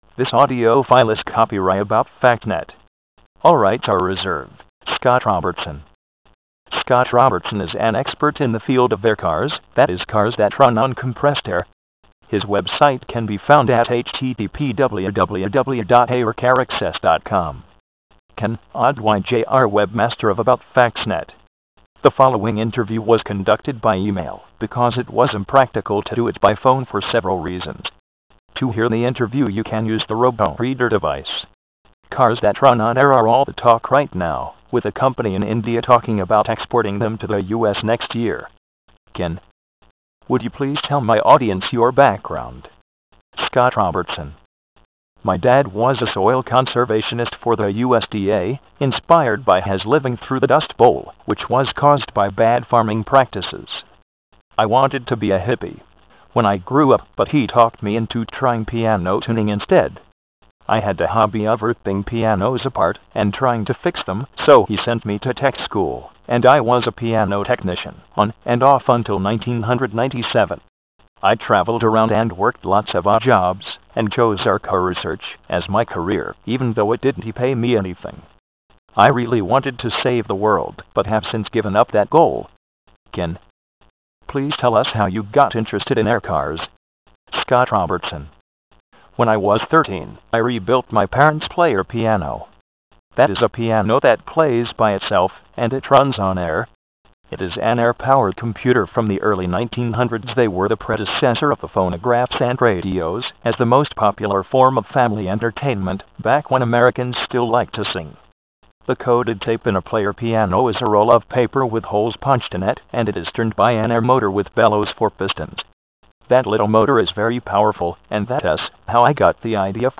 INTERVIEWS
Only a robotic voice is available for this interview.